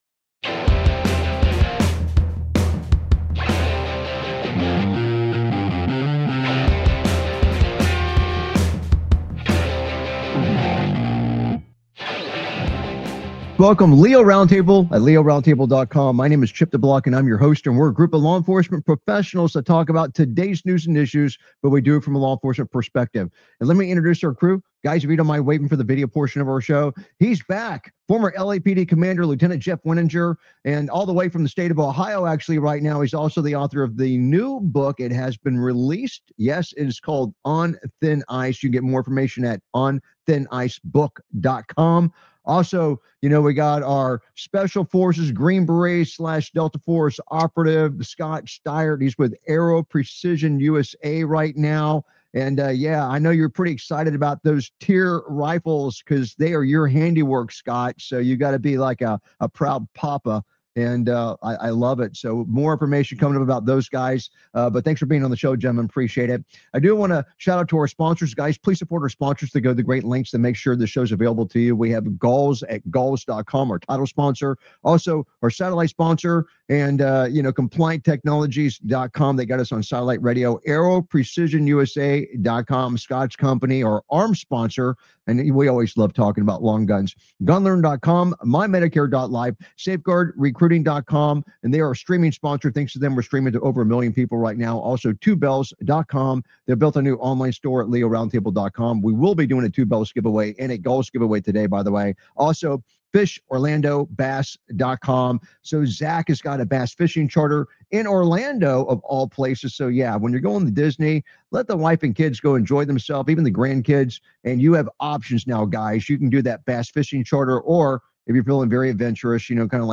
Talk Show Episode, Audio Podcast, LEO Round Table and S10E203, Bad Guy Tries Grabbing Gun Until Officers Began Shooting On Video on , show guests , about S10E203 Bad Guy Tries Grabbing Gun Until Officers Began Shooting On Video, categorized as Entertainment,Military,News,Politics & Government,National,World,Society and Culture,Technology,Theory & Conspiracy